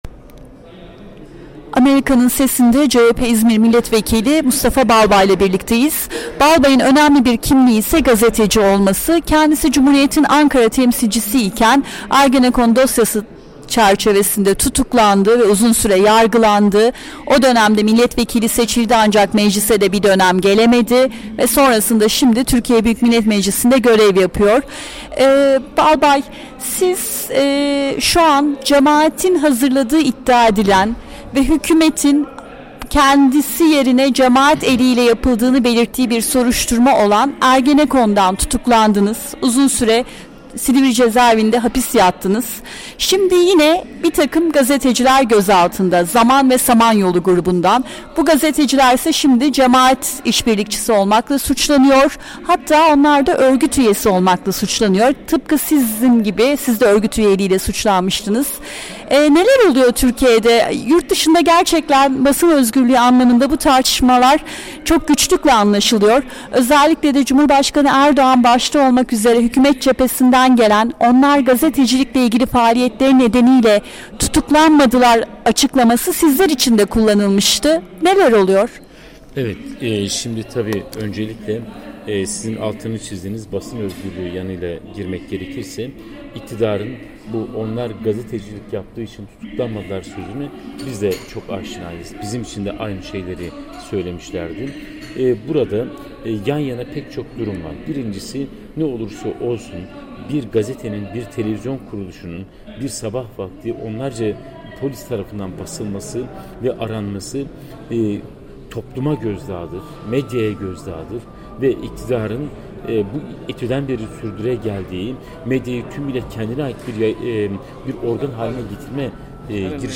Mustafa Balbay ile Söyleşi